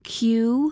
Phonics